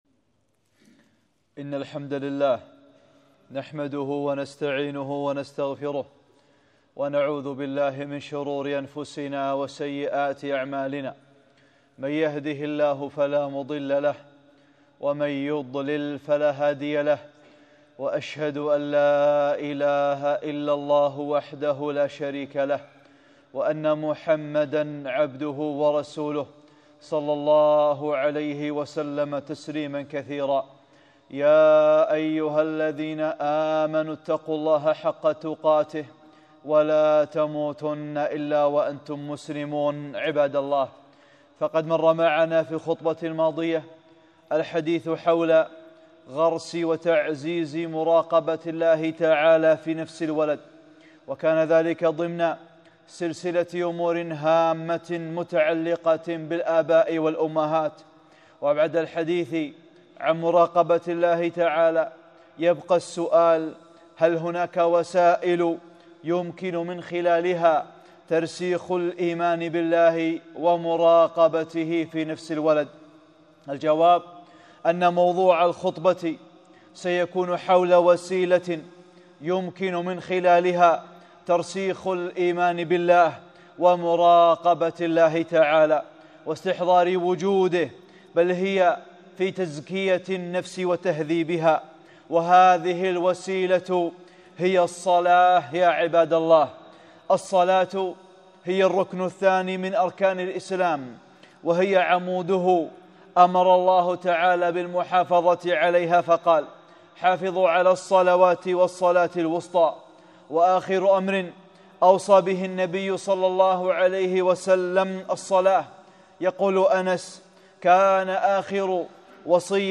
(28) خطبة - الصلاة | أمور هامة متعلقة بالآباء والأمهات